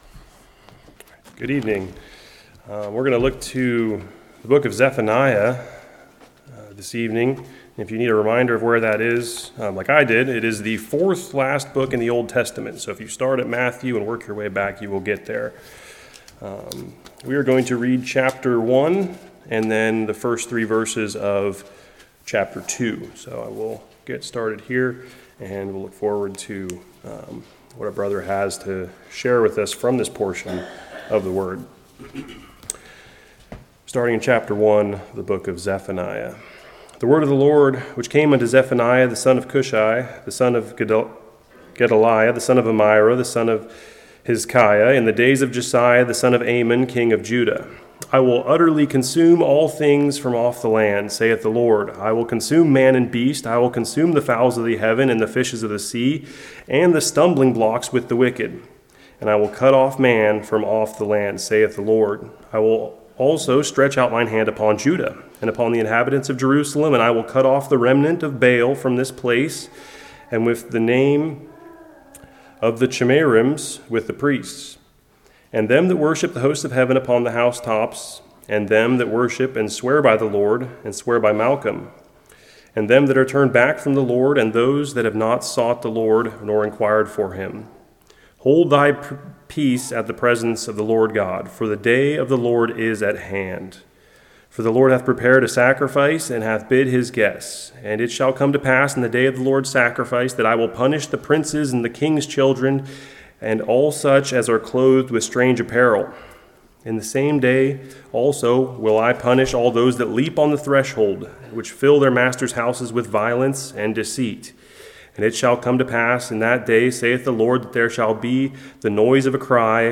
Zephaniah 1:1-2:3 Service Type: Evening What conditions led up to this prophecy?